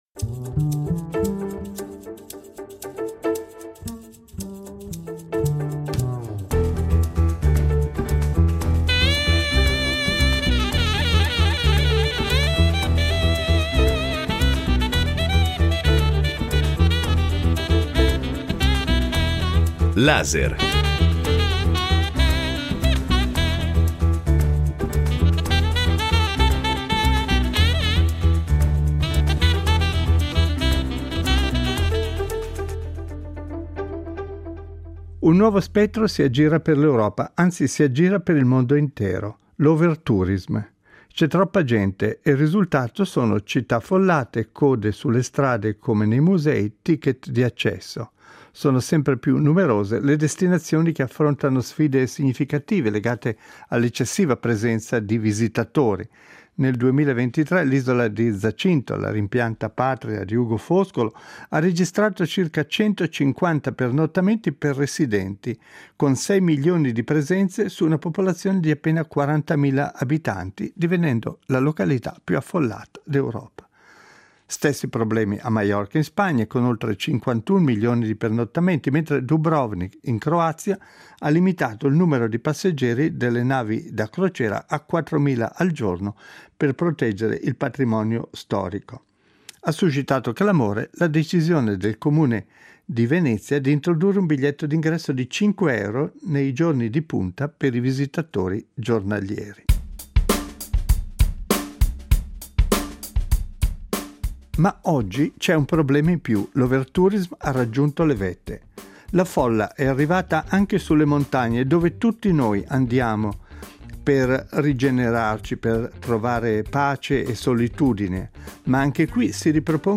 Nel corso di questo Laser vi proporremo le testimonianze di alcuni addetti ai lavori: guide alpine, direttori di stazioni, produttori di articoli sportivi, studiosi del paesaggio alpino, editori specializzati nei libri di montagna e direttori di testate specialistiche.